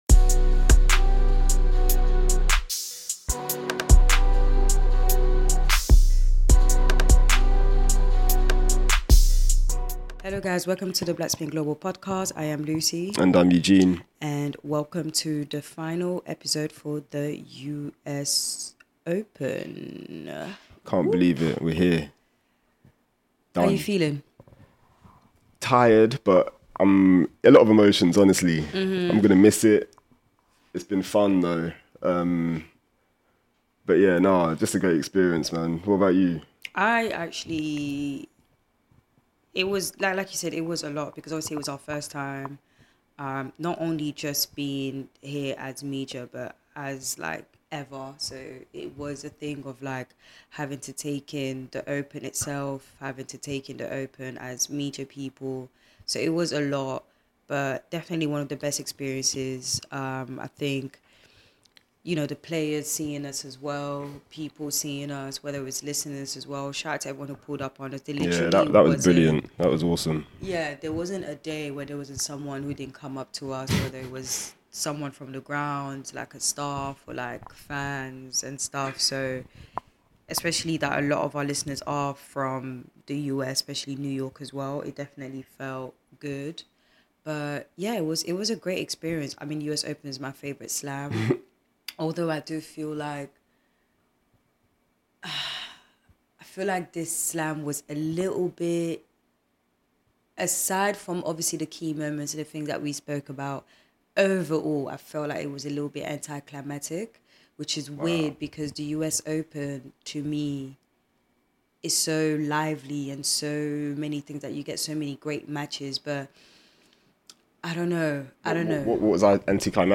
Listen out for post-match snippets from Young, Townsend and Tiafoe and to close we share our favourite fits, matches and moments from this year’s Open and discuss whether Honey Deuce is actually better than Pimm’s.